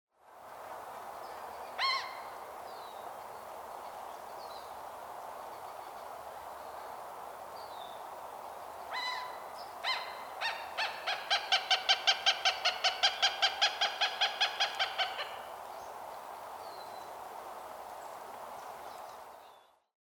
2. Cooper’s Hawk (Accipiter cooperii)
Call: A rapid, nasal “cak-cak-cak,” especially during nesting season.